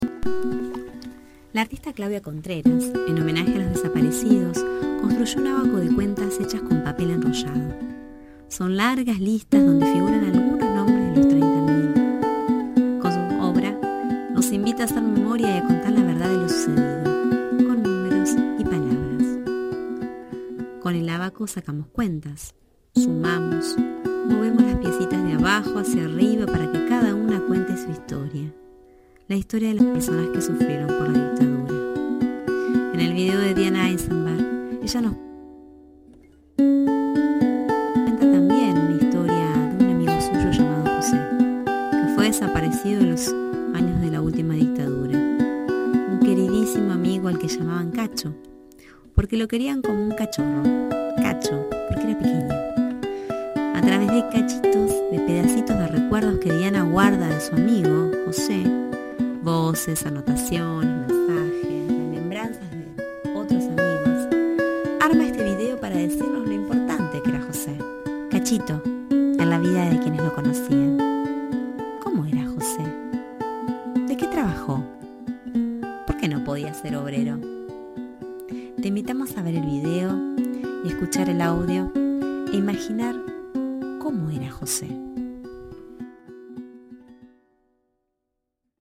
Audioguía niños